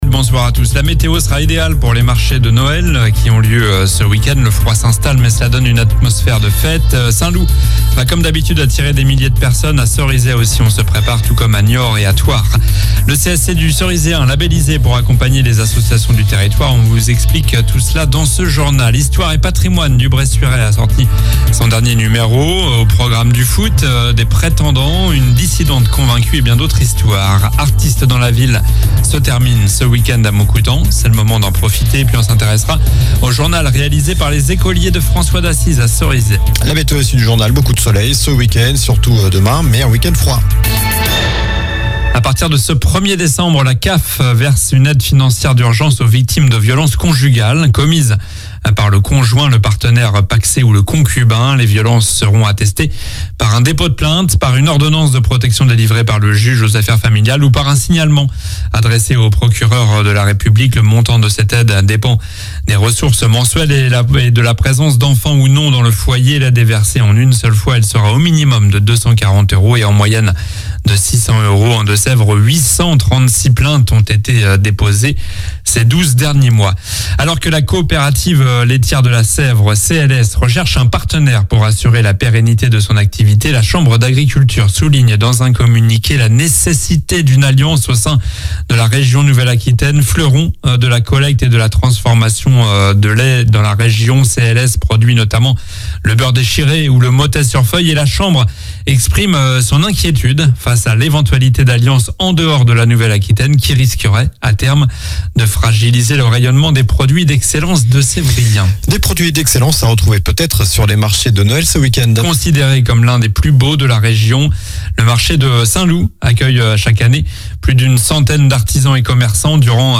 Journal du vendredi 1er décembre